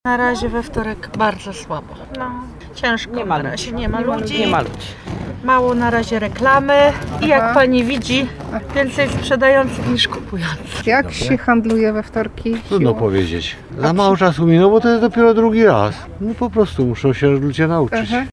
Wybraliśmy się sprawdzić, jak nowy dzień handlowy się przyjął.
Ci pierwsi nie tracą jednak ducha i mówią, że to dopiero początek i trzeba trochę czasu, aby wtorkowy handel się rozkręcił.